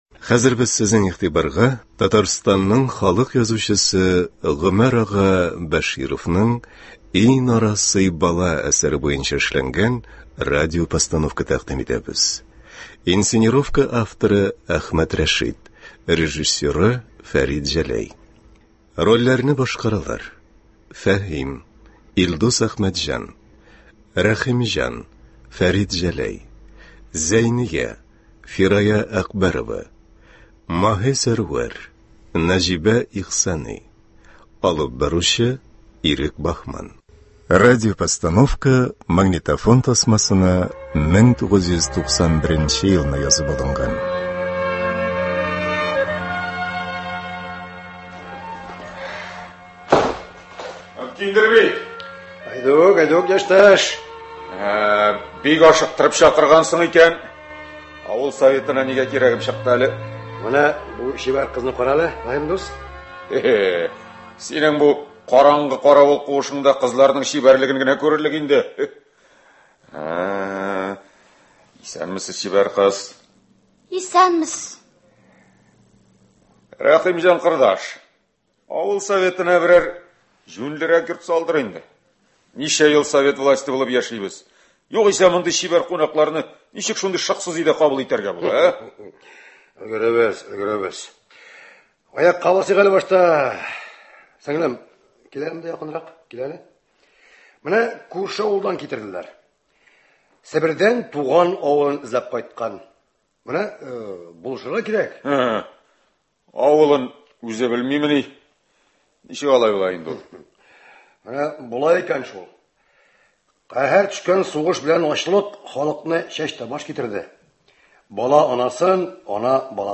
Радиотамаша.
Игътибарыгызга Татарстанның халык язучысы Гомәр ага Бәшировның “И, нарасый бала!..” әсәре буенча эшләнгән радиопостановка тәкъдим итәбез. 1991 елда Татарстан радиосы студиясендә магнитофон тасмасына язып алынган бу радиотамашаның инсценировка авторы